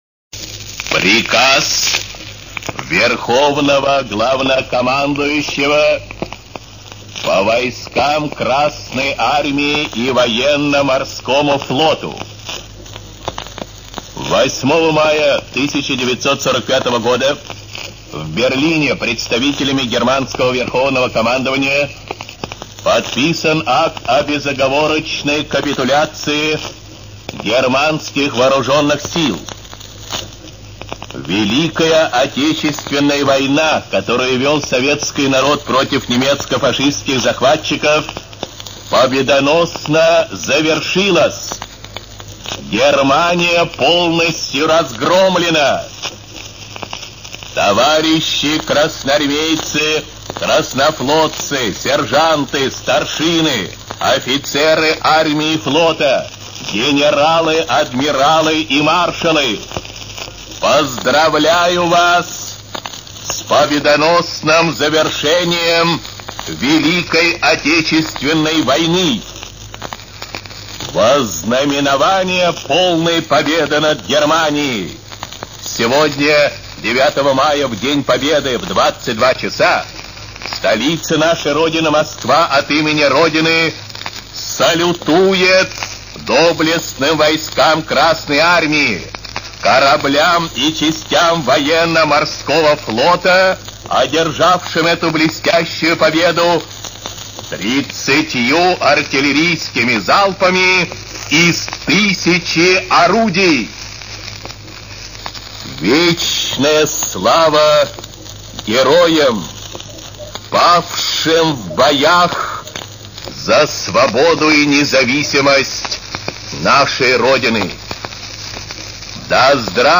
С праздником Победы! Прослушайте сообщение Юрия Борисовича Левитана от 09.05.1945 г.